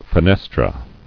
[fe·nes·tra]